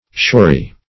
shory - definition of shory - synonyms, pronunciation, spelling from Free Dictionary Search Result for " shory" : The Collaborative International Dictionary of English v.0.48: Shory \Shor"y\, a. Lying near the shore.